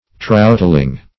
Troutling \Trout"ling\ (-l[i^]ng), n. A little trout; a troutlet.